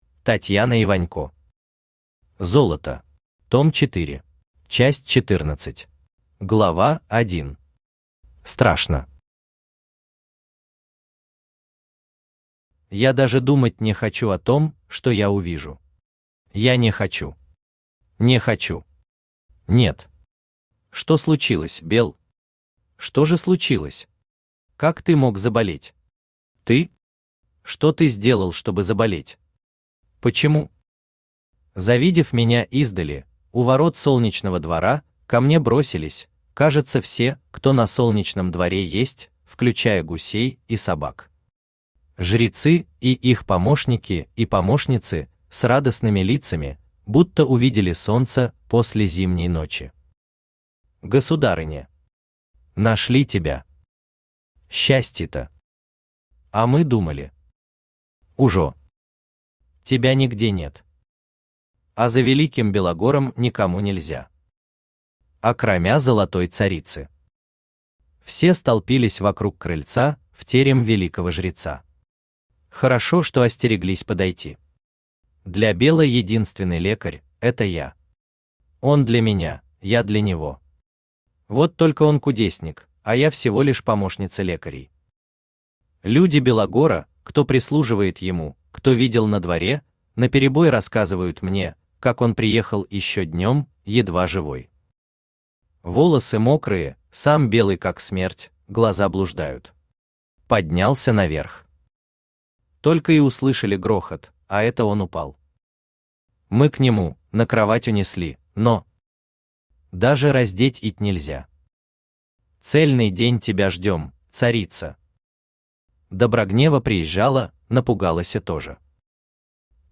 Аудиокнига Золото. Том 4 | Библиотека аудиокниг
Aудиокнига Золото. Том 4 Автор Татьяна Вячеславовна Иванько Читает аудиокнигу Авточтец ЛитРес.